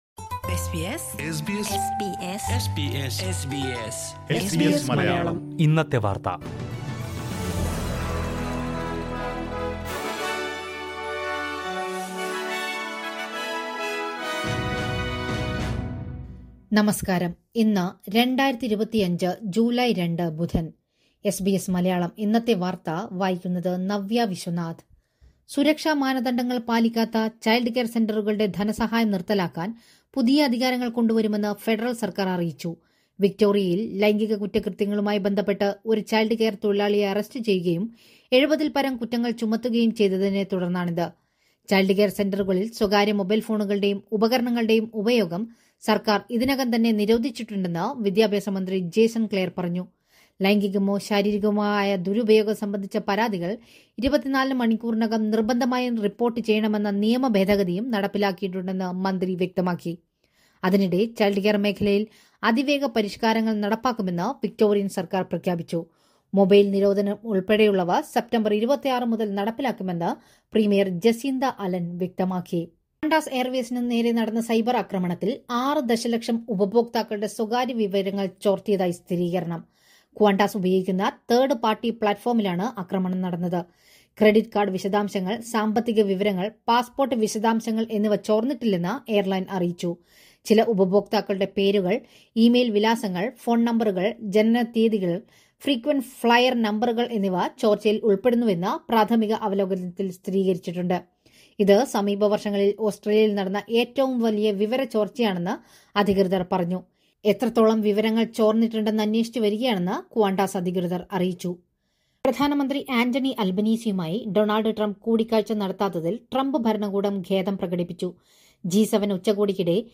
.2025 ജൂലൈ രണ്ടിലെ ഓസ്‌ട്രേലിയയിലെ ഏറ്റവും പ്രധാന വാര്‍ത്തകള്‍ കേള്‍ക്കാം...